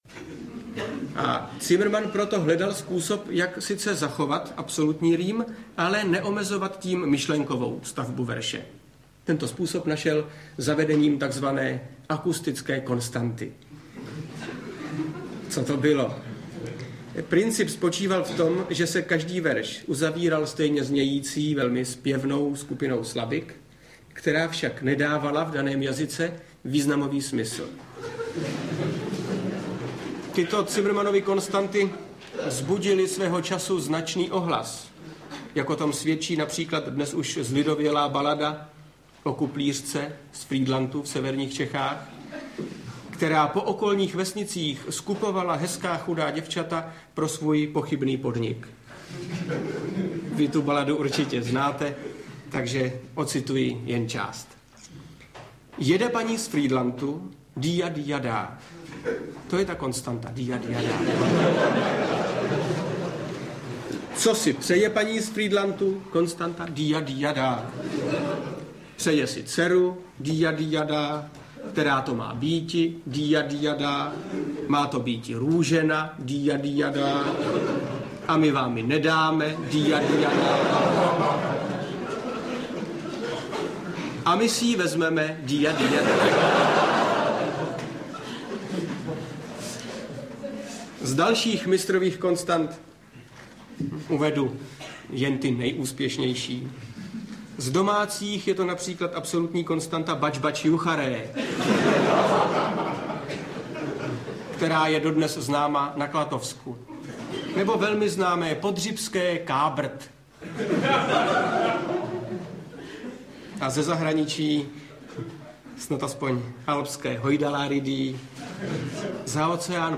Ukázka z knihy
Audiokniha Hospoda na mýtince - obsahuje záznam divadelního představení dnes už legendárního Divadla Járy Cimrmana, jehož hlavními postavami jsou Zdeněk Svěrák a Ladislav Smoljak.